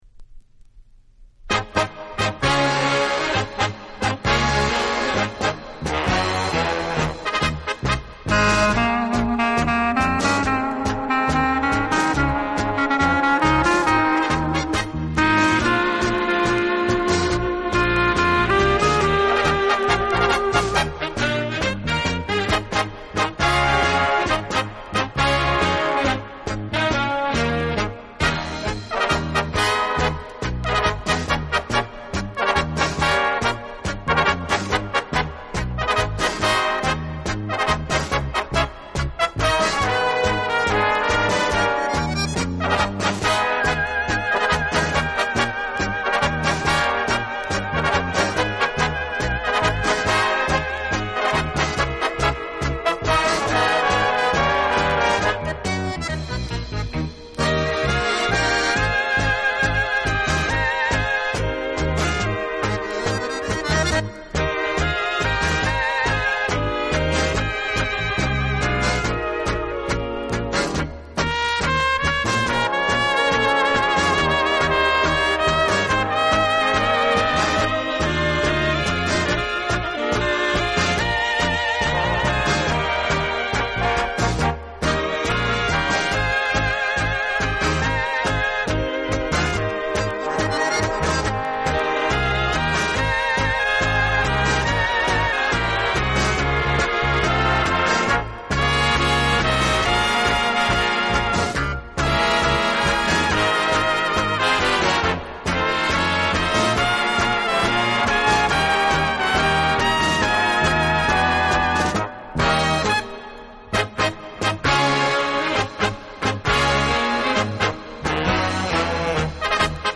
(Tango)